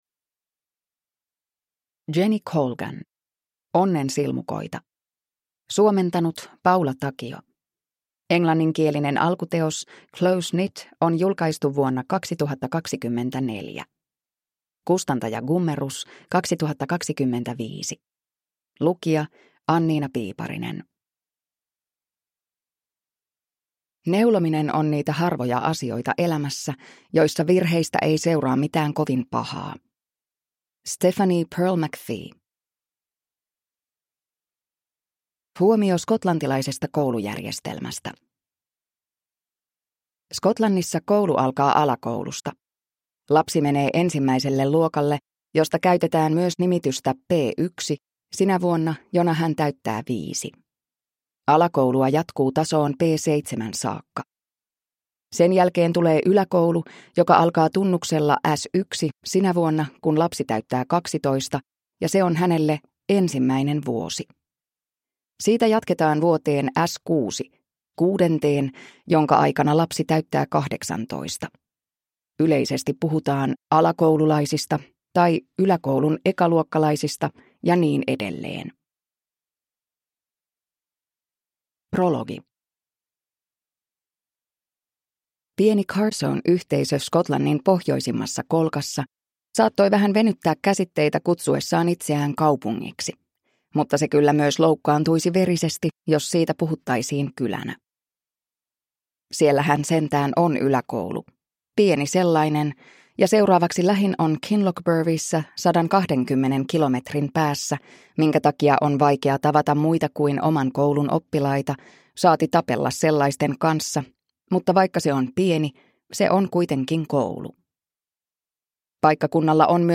Onnen silmukoita (ljudbok) av Jenny Colgan